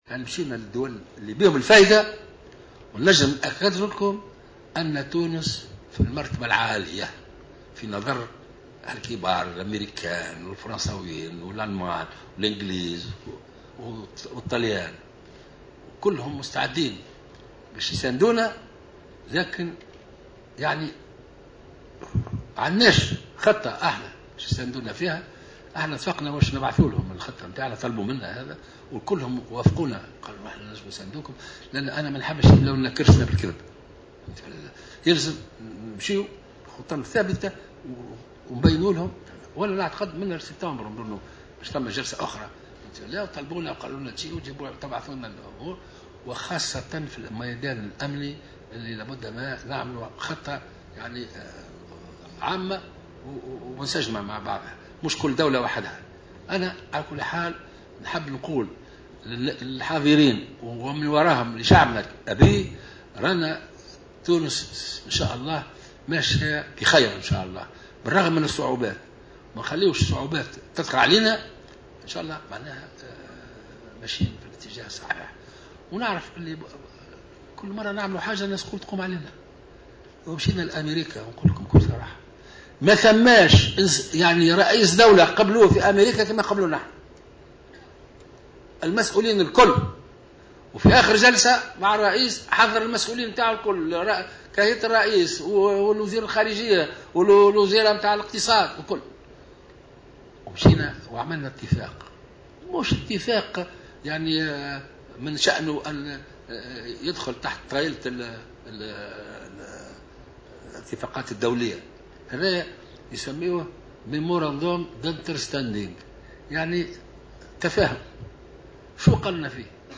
واستغرب قائد السبسي في كلمة ألقاها بمناسبة افتتاحه ملتقى للمعهد التونسي للدراسات الإستراتيجية، انتقادات منظمات في تونس للاقتراح الأميركي بشان الناتو وتهديدها بإسقاط هذا المشروع في مجلس نواب الشعب، موضحا أن هذا الأمر مجرّد اقتراح وجاء في مذكرة تفاهم ولا يرتق لأن يصبح قانونا.